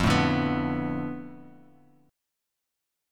F6b5 chord